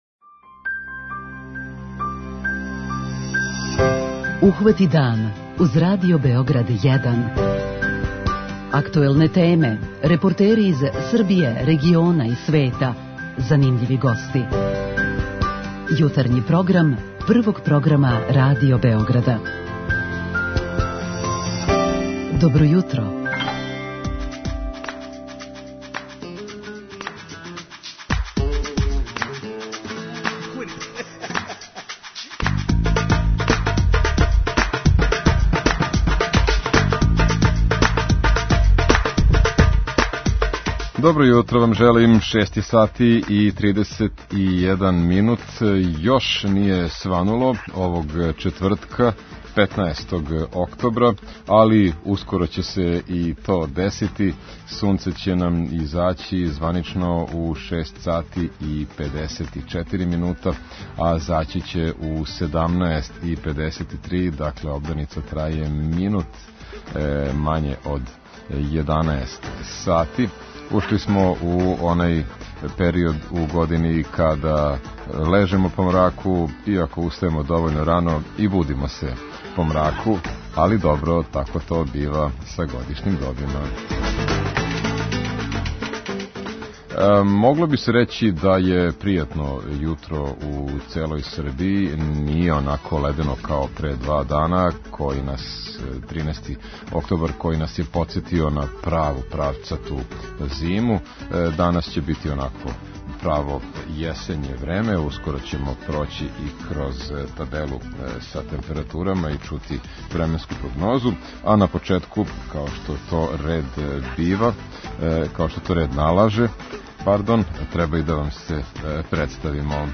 Говорићемо о производњи дезинфицијенаса, али и о томе како наћи праву меру у прању руку и не претерати. О овој теми причаћемо и са слушаоцима у редовној рубрици 'Питање јутра'.
Поводом Светског дана белог штапа имаћемо репортерско укључење из Градског савеза слепих чији ће нам представници пренети како живе и с којим се изазовима суочавају слепи и слабовиди.